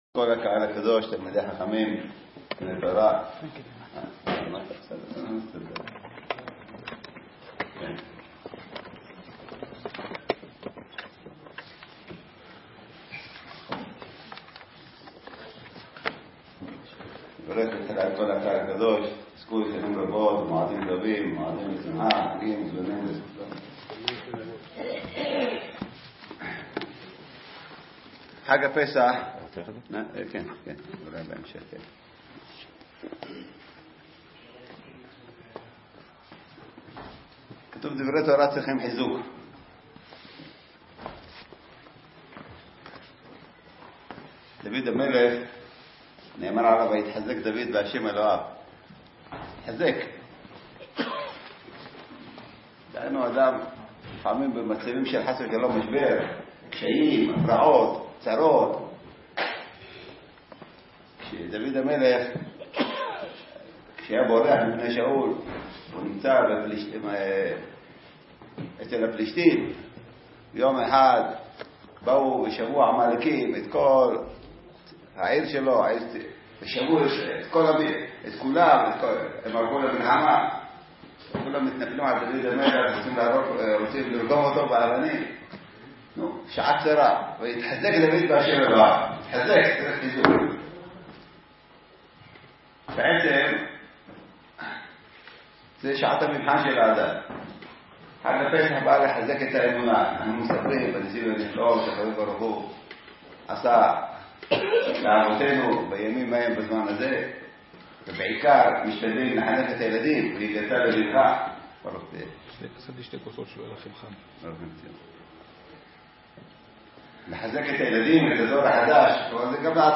חדש! שיעור מפי מרן שליט"א - חול המועד פסח ה'תשע"ט - רמת שלמה, ירושלים ת"ו